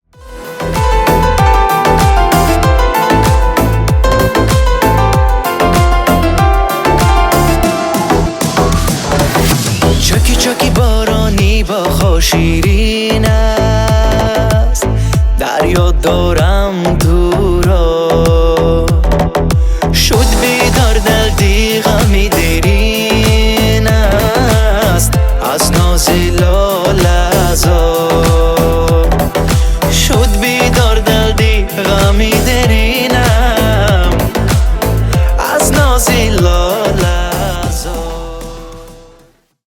Танцевальные # таджикские